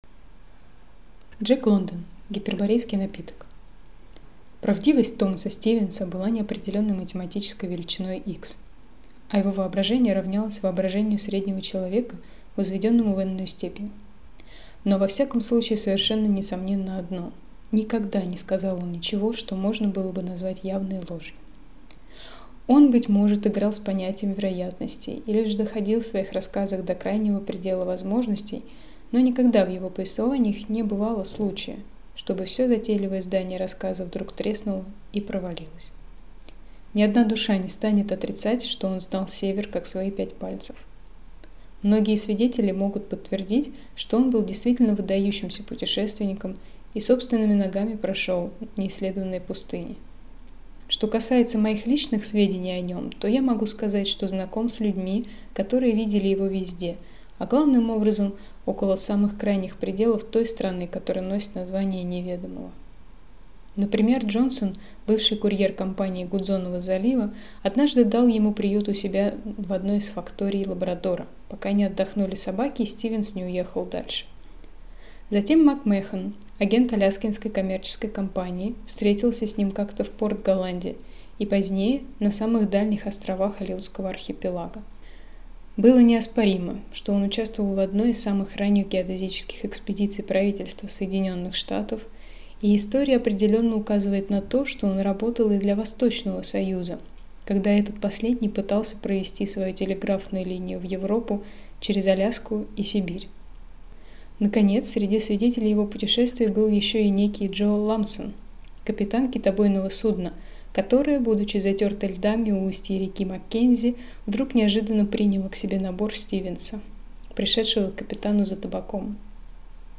Аудиокнига Гиперборейский напиток | Библиотека аудиокниг